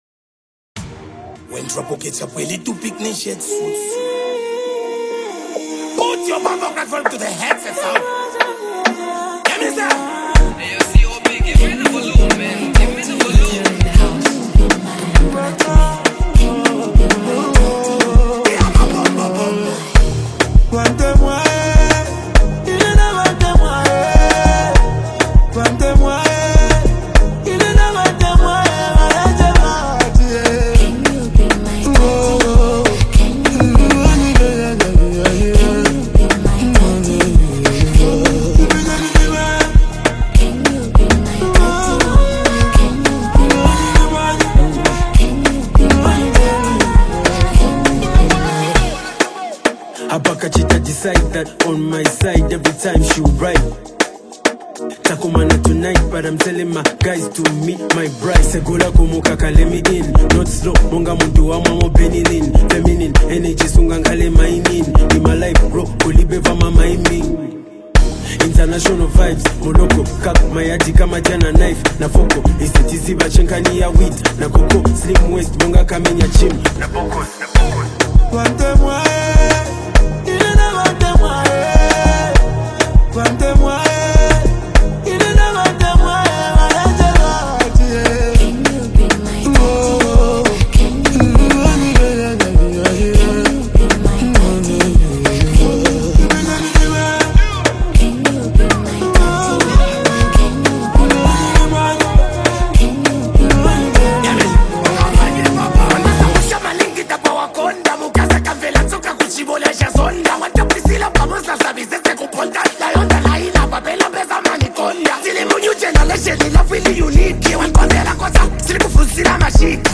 smooth vocals that add a melodic balance